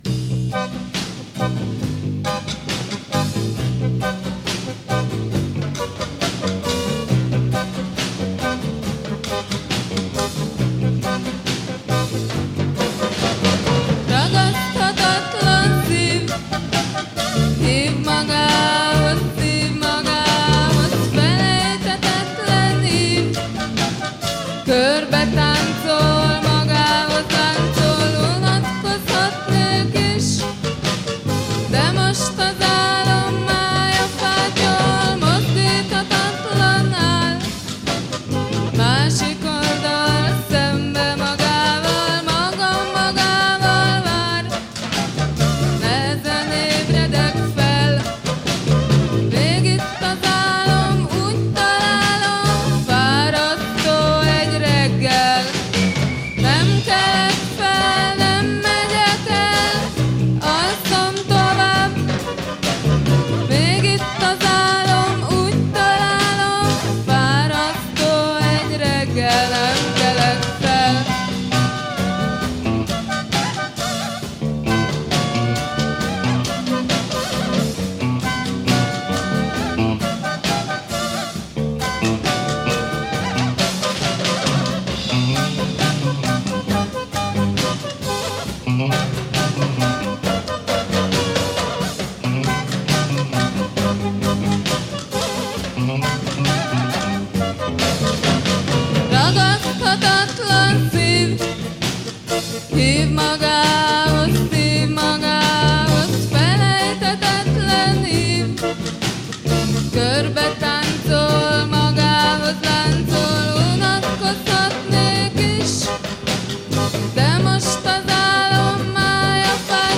un groupe underground, d'avant-garde